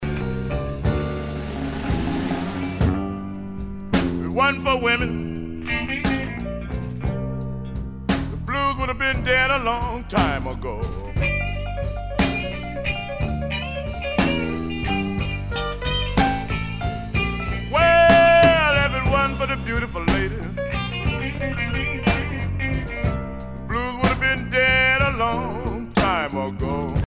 danse : slow
Pièce musicale éditée